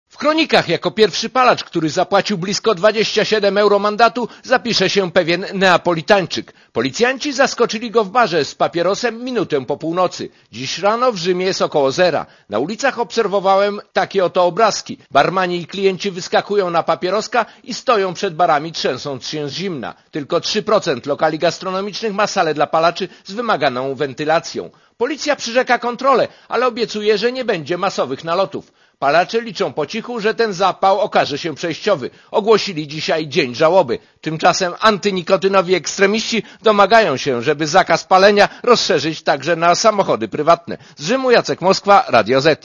wlochy_-_palenie.mp3